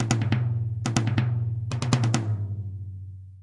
鼓声循环 5
描述：鼓循环定时器